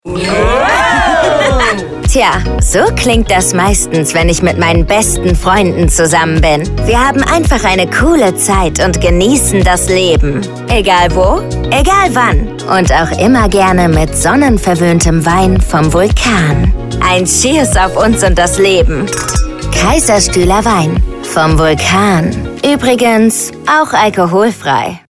Hier spricht die gewählte Sprecherin